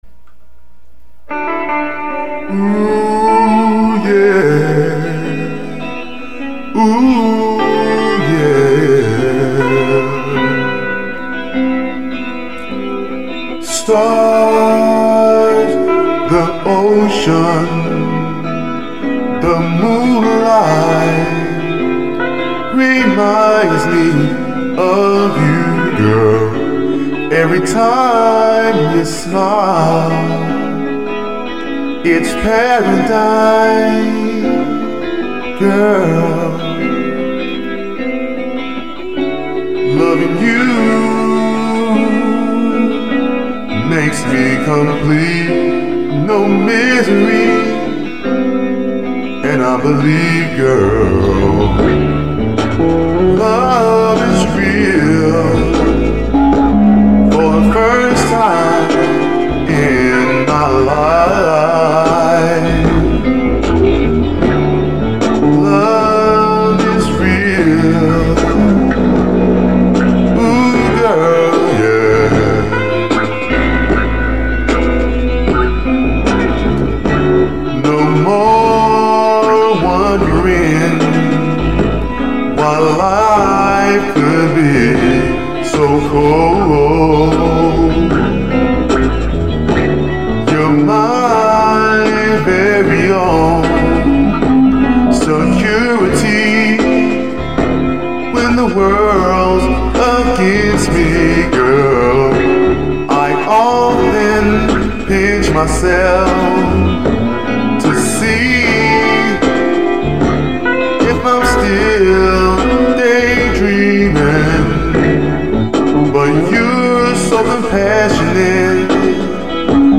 ROMANCE LOVE